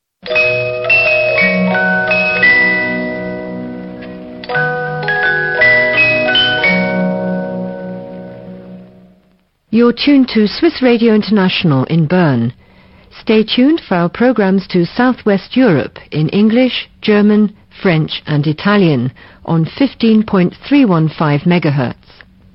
... GRUNDIG Satellit 2000 auf Madeira im 19m-Band